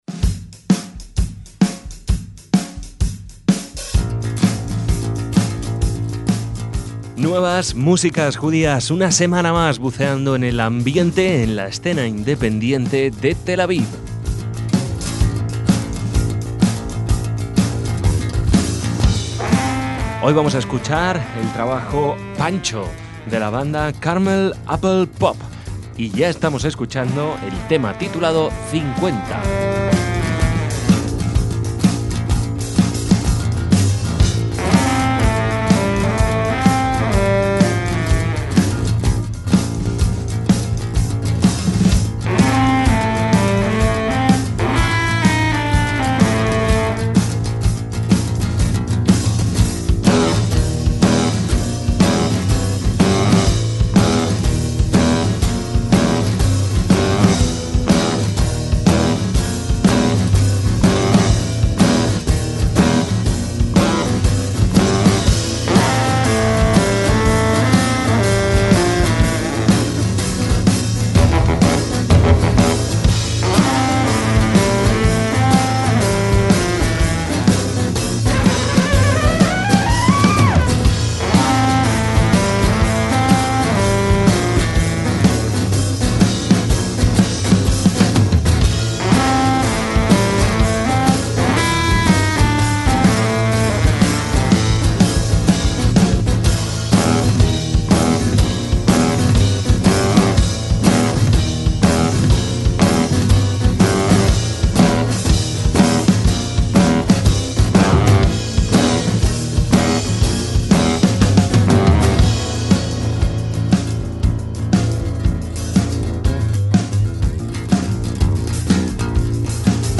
guitarra
y saxofón